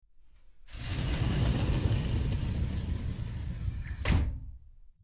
Glass Door Slides Open
Glass_door_slides_open.mp3